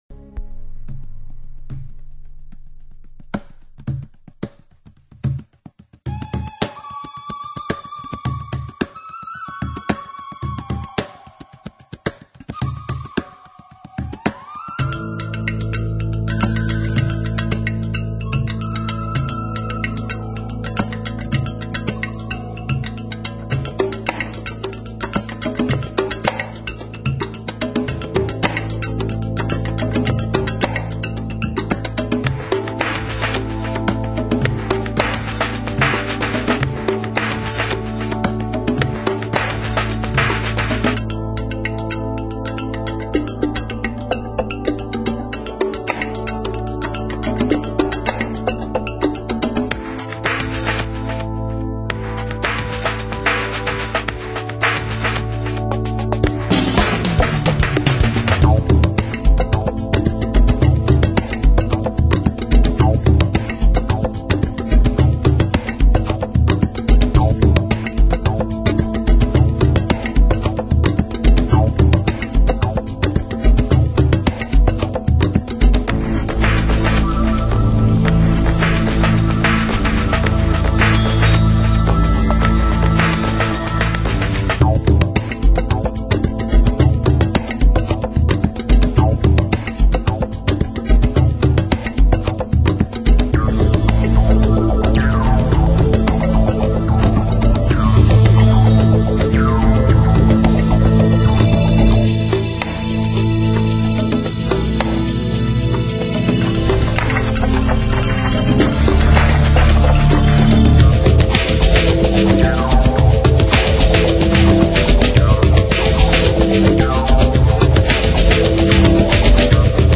散发出浓浓的东方味以及神圣气氛。
印度的 Ragga ,佛教 的梵唱 ,日本的三弦 ,中国传统国乐,还有非洲的敲击乐 . . .